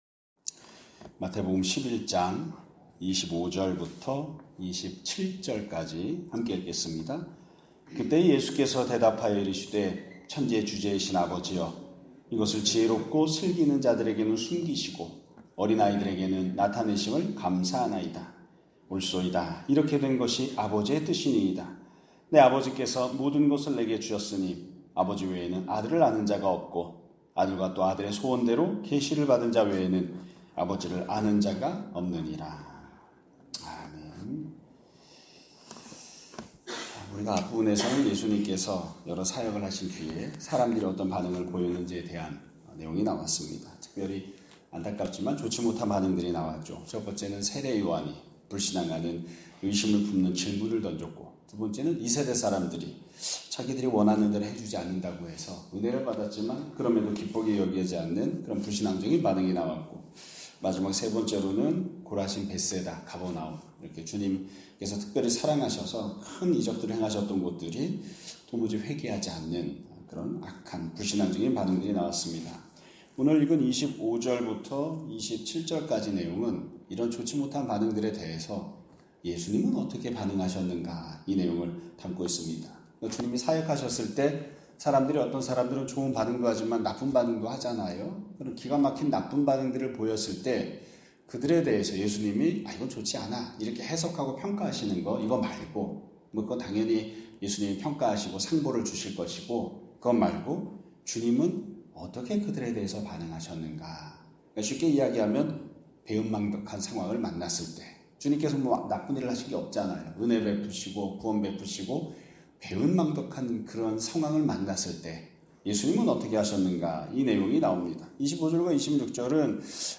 2025년 9월 1일 (월요일) <아침예배> 설교입니다.